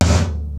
Tom-05.wav